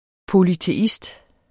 Udtale [ polyteˈisd ]